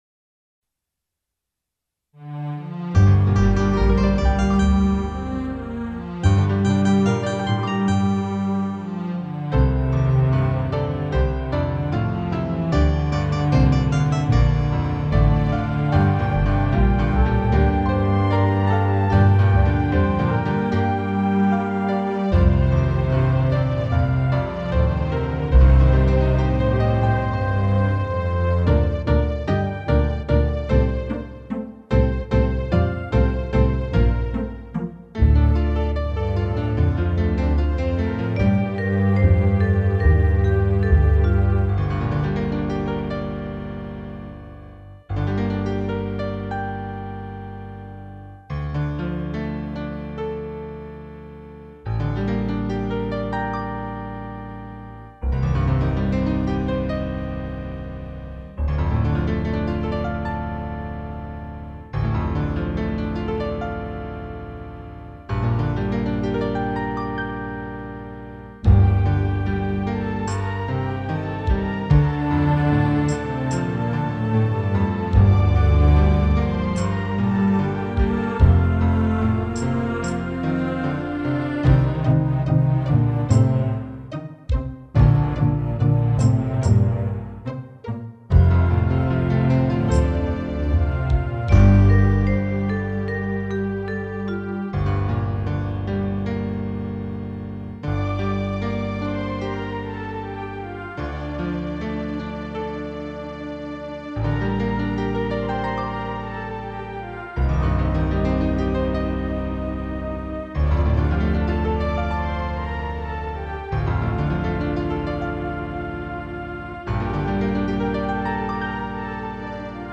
(a) 伴奏